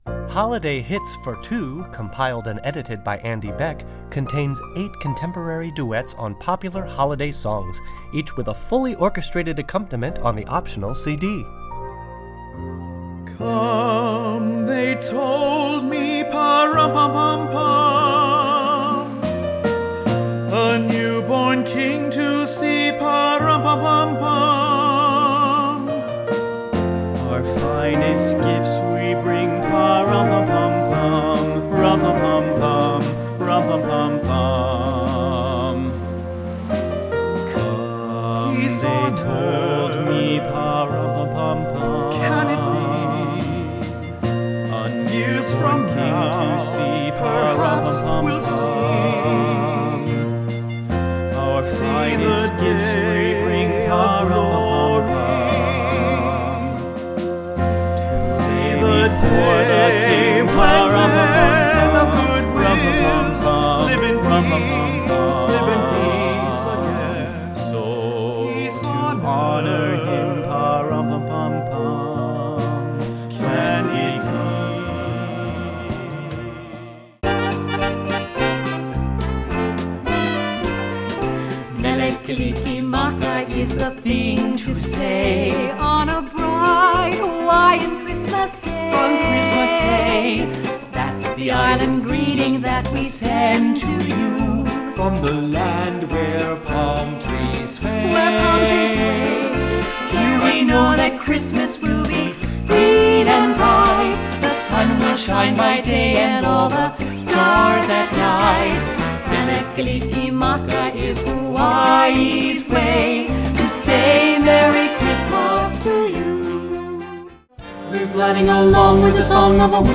Voicing: Vocal Duet Book and CD